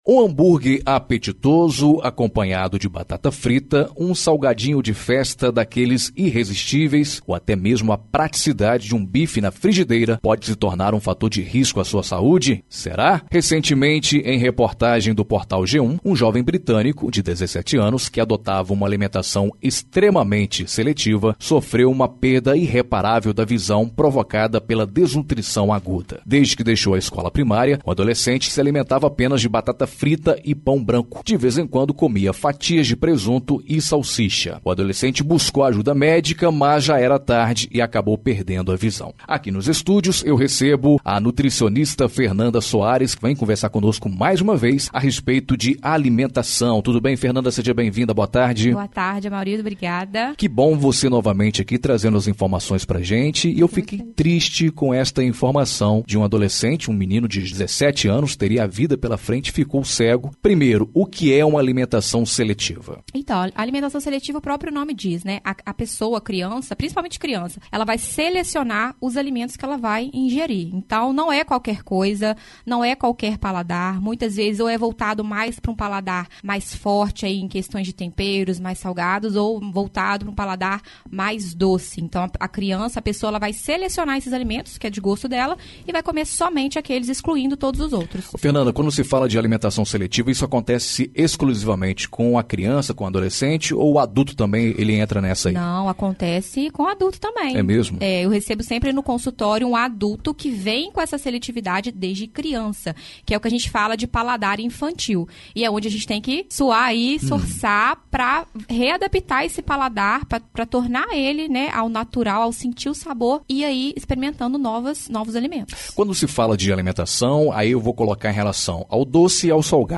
Matéria relacionado na entrevista